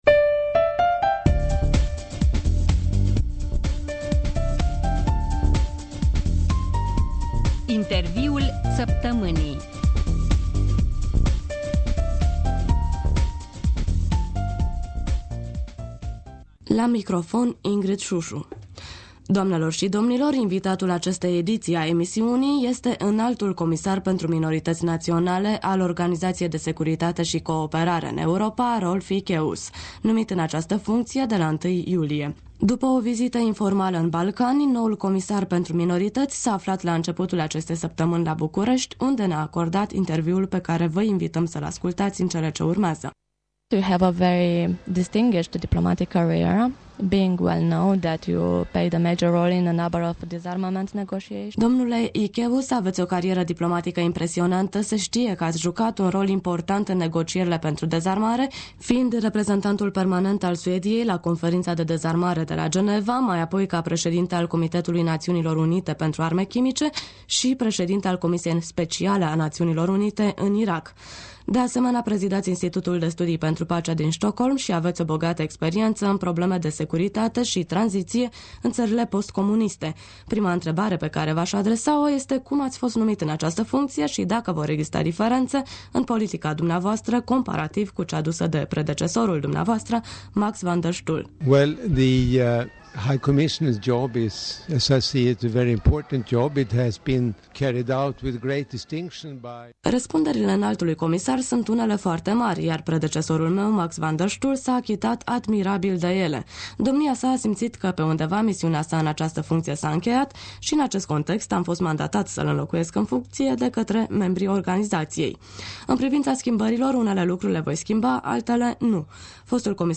Interviul săptămînii: cu Rolf Ekeus